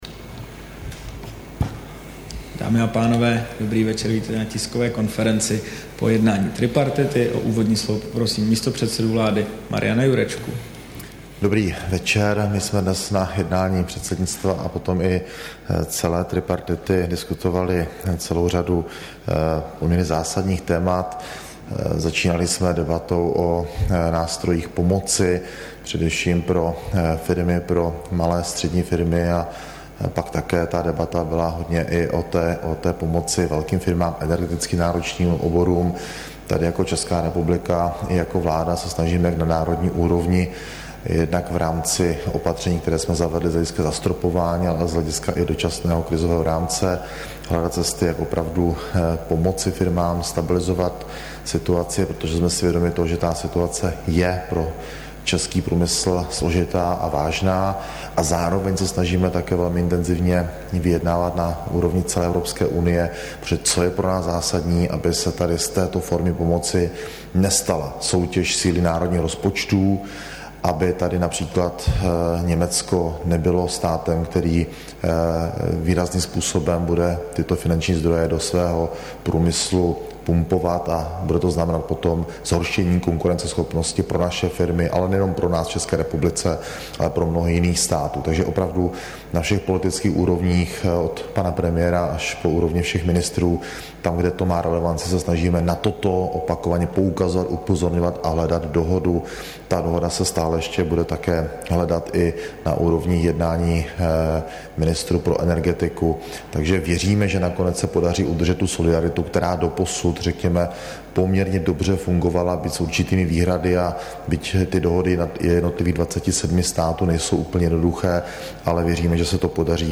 Tisková konference po jednání tripartity, 14. listopadu 2022